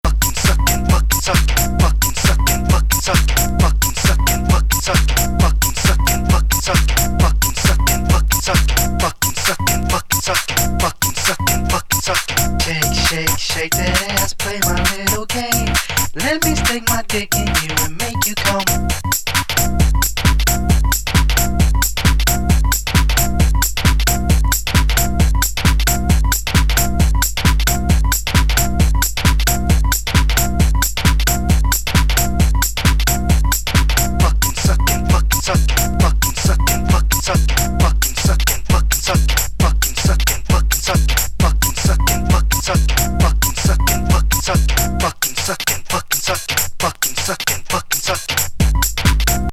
シカゴ・ゲットー・ハウス最高峰94年作!